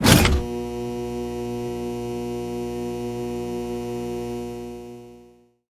На этой странице представлена коллекция звуков рубильника — от четких металлических щелчков до глухих переключений.
Звук включенного рубильника в пустом большом помещении